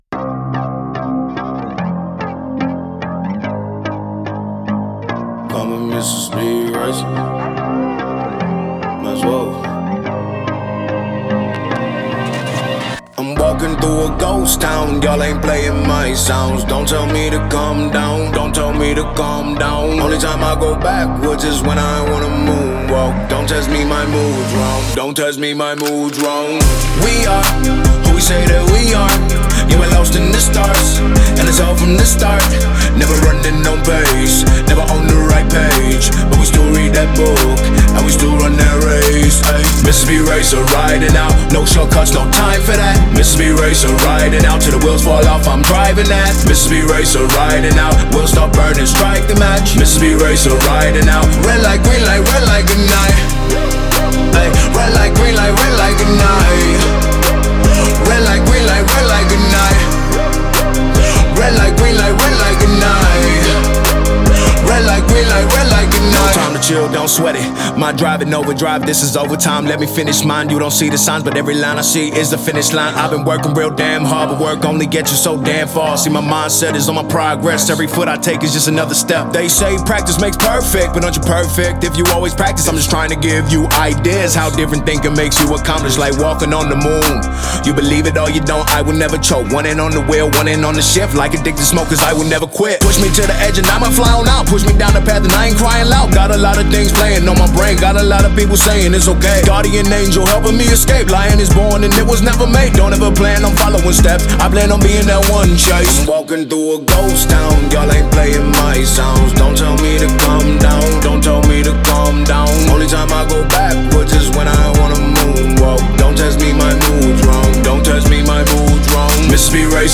ژانرهای :  هیپ هاپ / رپ